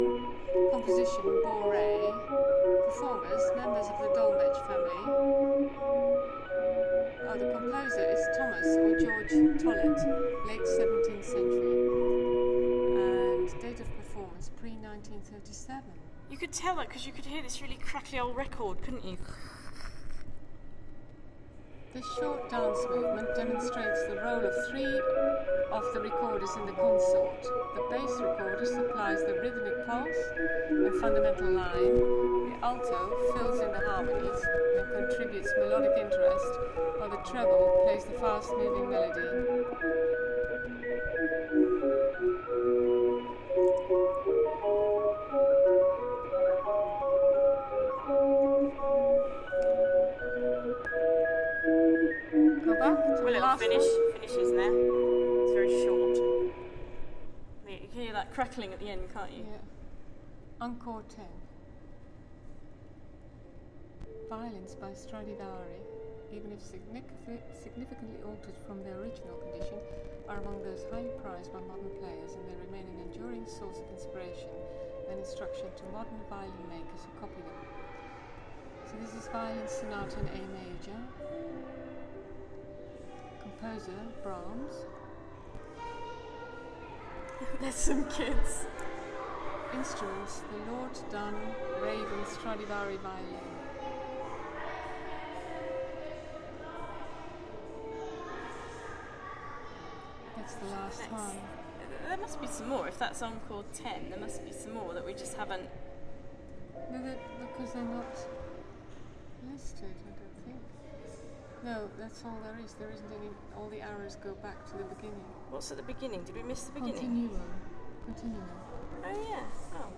Horniman 8. The Art of Harmony. More info and music examples, then info on the English Guitar